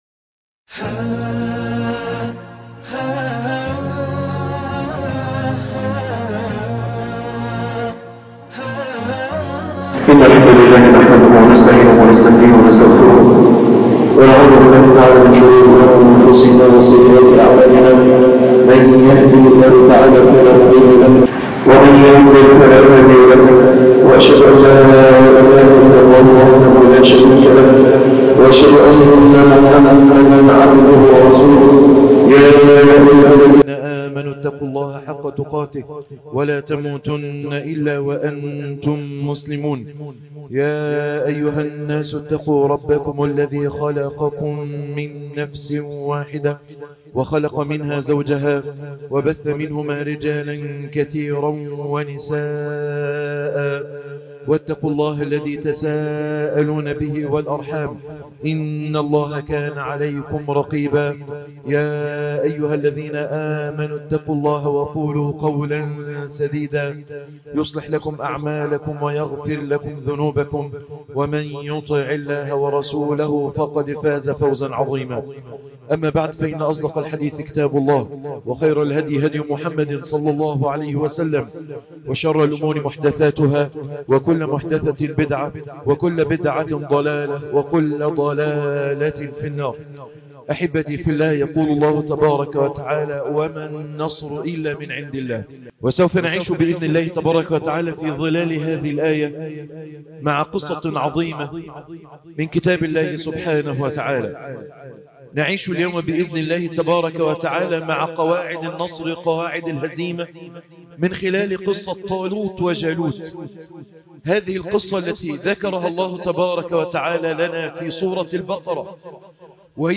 دروس وعبر من قصة طالوت وجالوت - خطب الجمعة